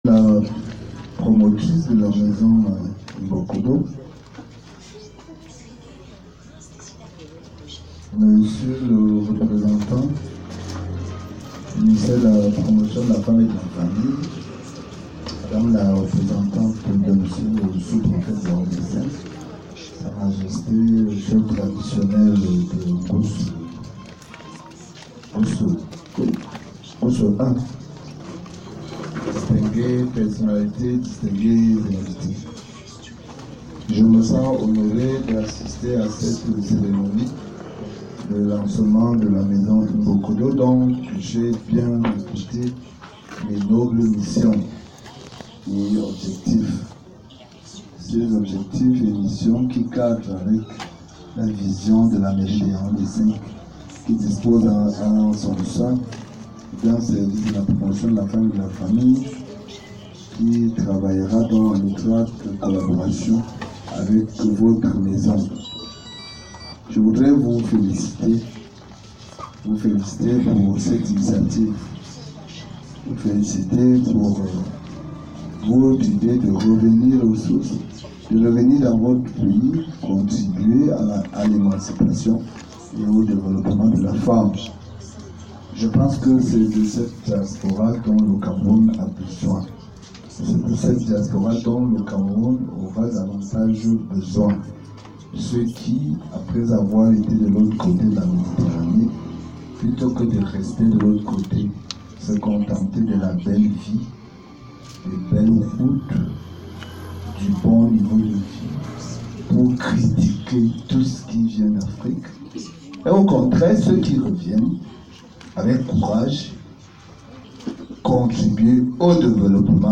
Réaction 🎙
Augustin Bala, maire de Yaoundé 5